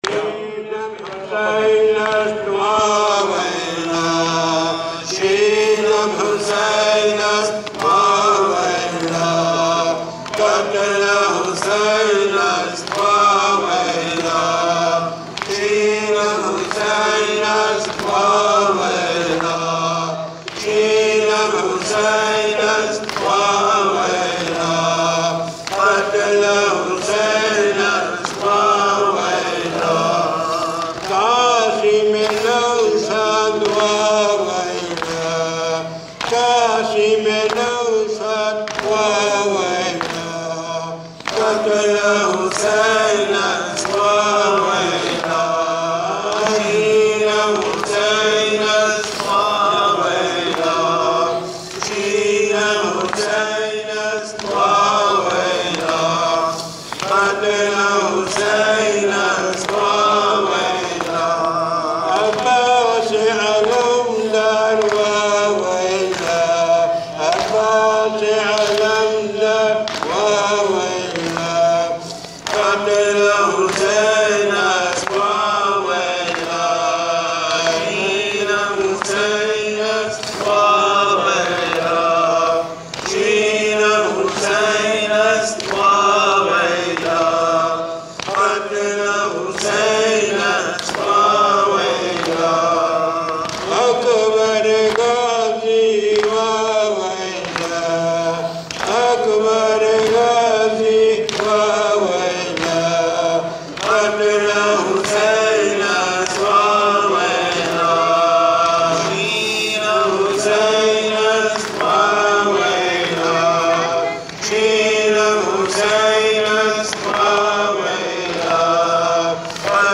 (to correct the wordings for this nawha, click here)